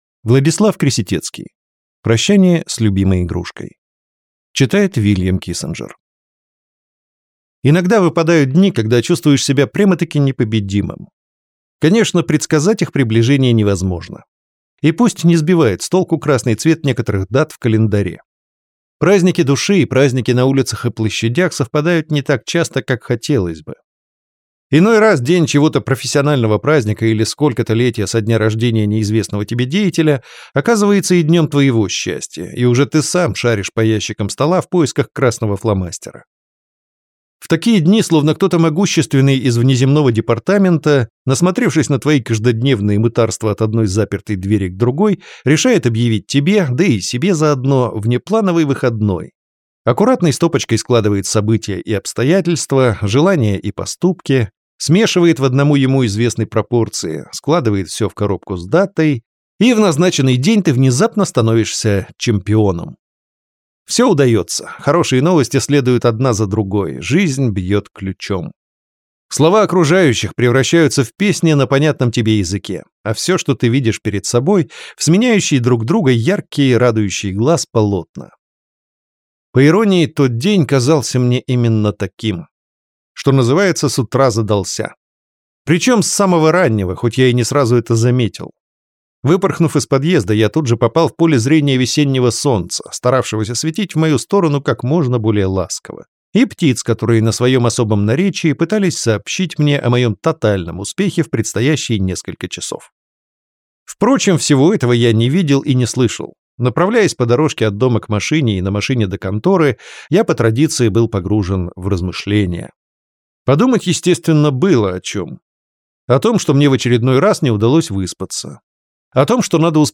Аудиокнига Прощание с любимой игрушкой | Библиотека аудиокниг